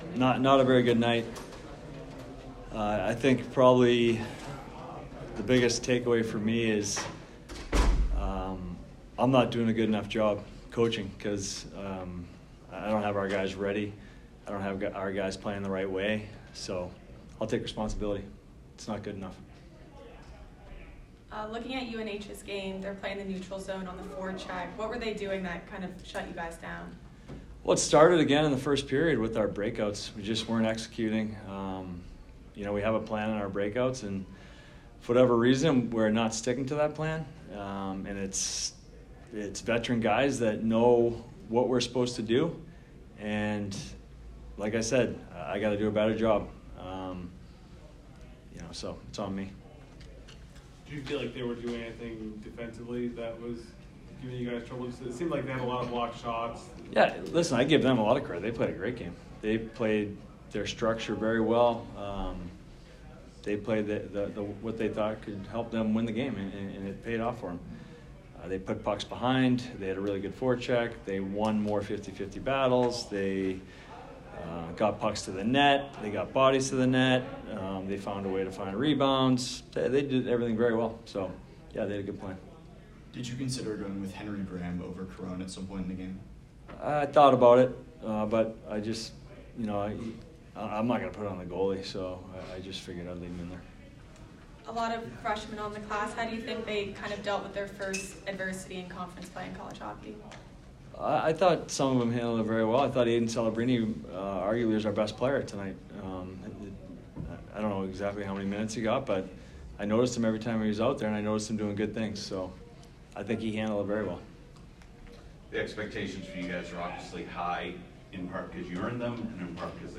Men's Ice Hockey / UNH Postgame Interview (10-13-23)